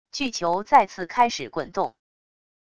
巨球再次开始滚动wav音频